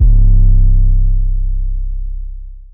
Teck-808 (purpp).wav